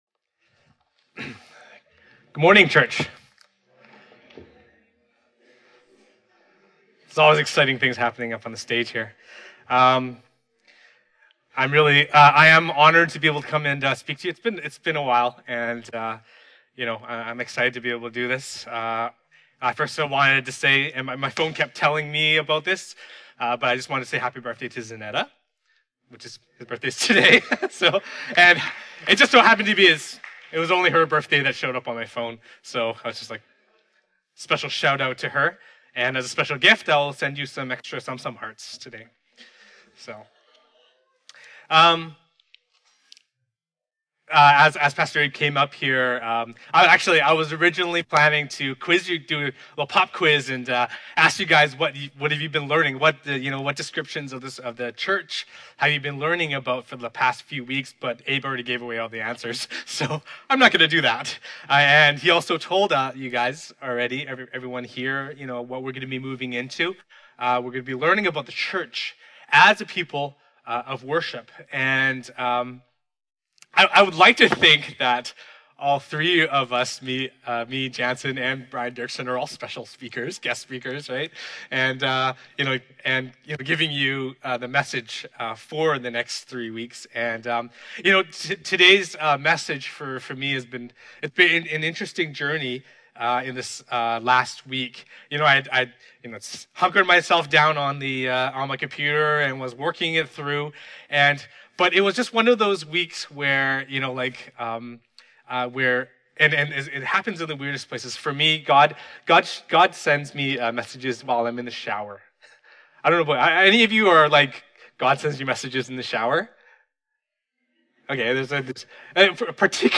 Fraser Lands Church Worship Service & Sermon Podcast | Fraser Lands Church